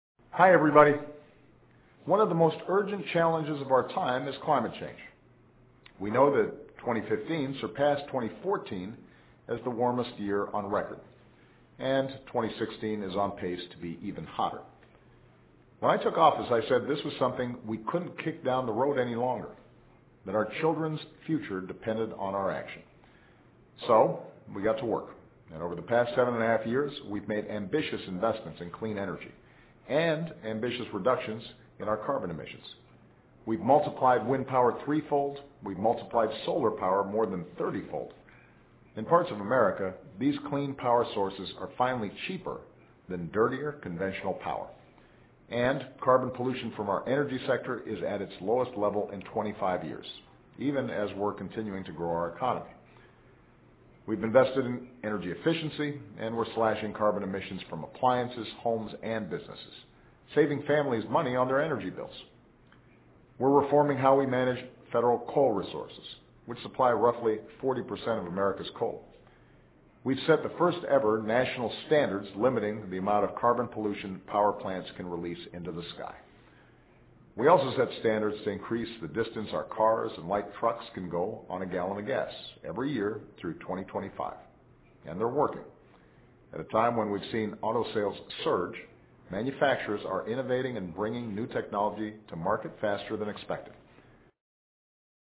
奥巴马每周电视讲话：总统呼吁给孩子美好清洁安全的未来（01） 听力文件下载—在线英语听力室